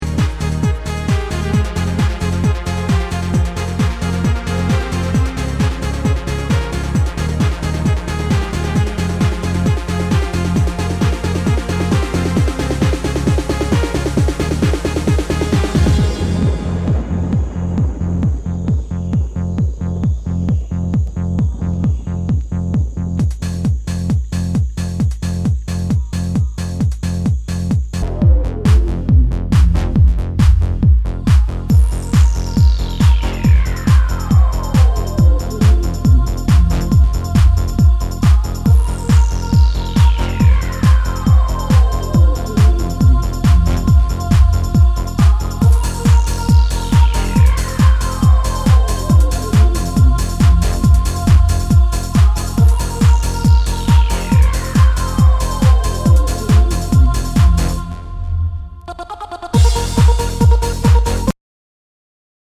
HOUSE/TECHNO/ELECTRO
ナイス！ハード・トランス！